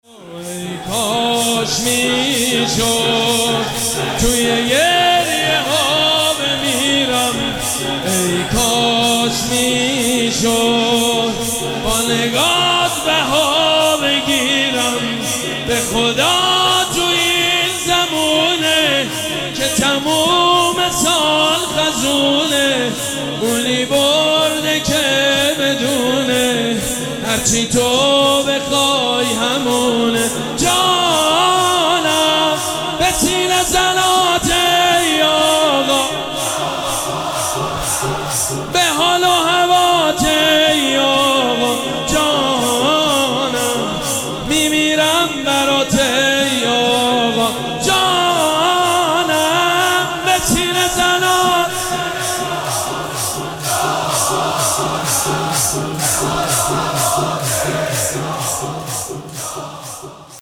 مراسم عزاداری شب اول محرم الحرام ۱۴۴۷
شور
مداح
حاج سید مجید بنی فاطمه